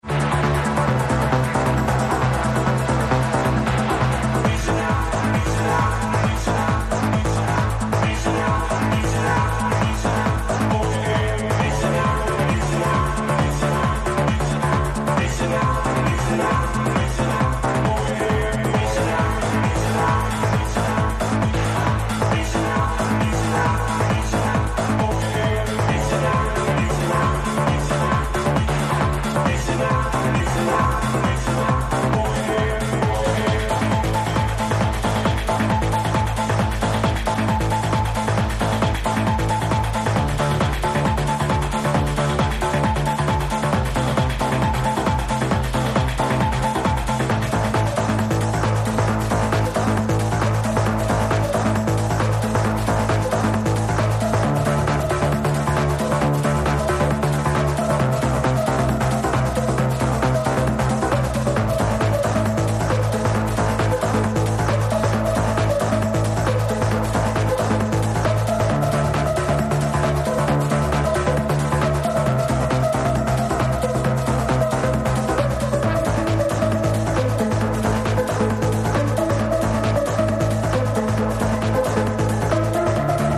Surely played by Ferry Corsten, Armin Van Buuren, Tiesto.